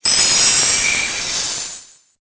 infinitefusion-e18/Audio/SE/Cries/FROSMOTH.ogg at a50151c4af7b086115dea36392b4bdbb65a07231